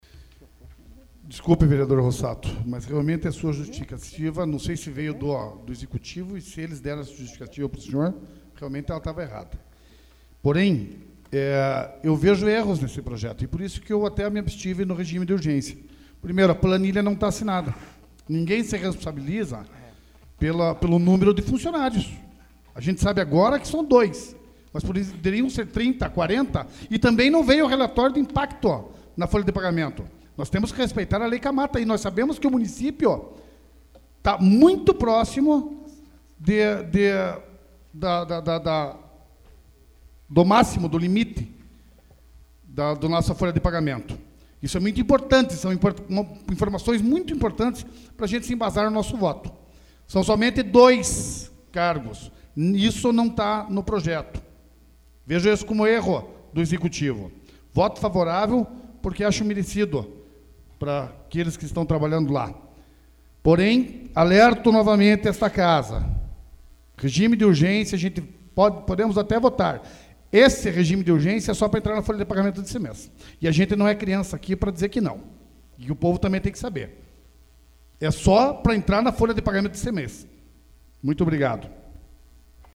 Discussão do Projeto AVULSO 25/03/2014 Junior Torres